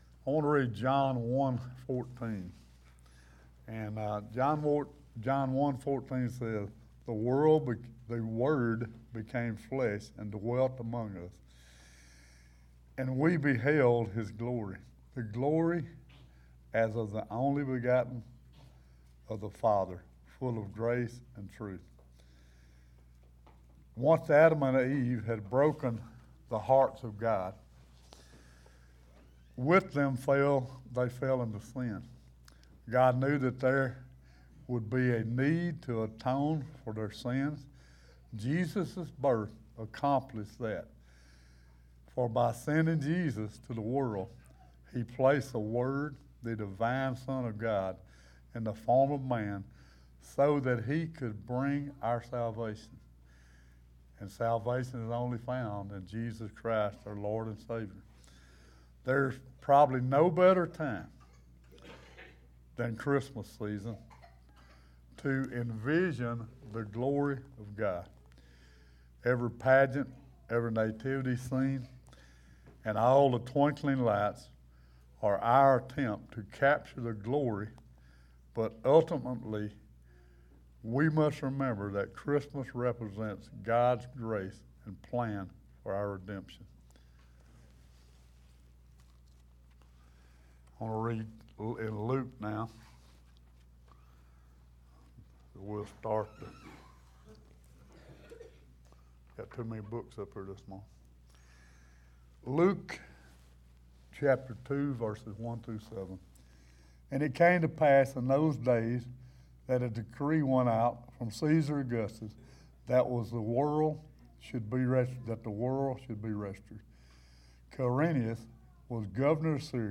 12/24/23 Candlelight Service